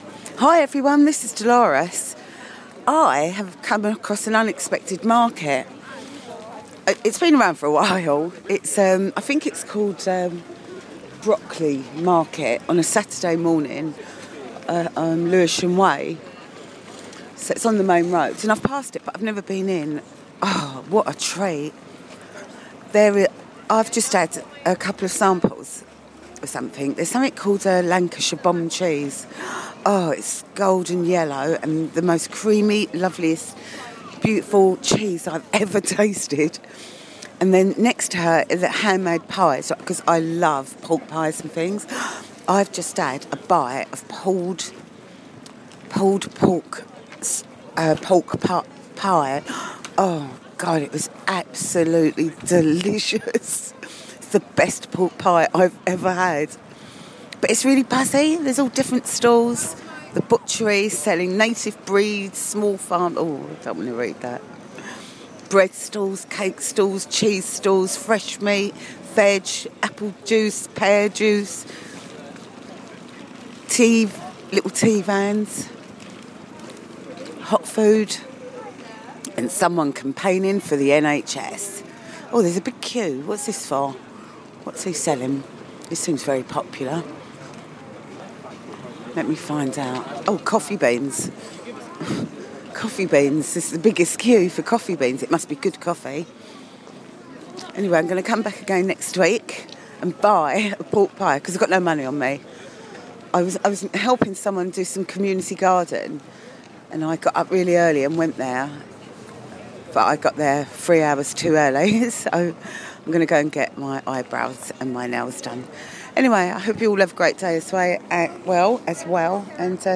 I'm at Brockley food market on Lewisham Way. What a little gem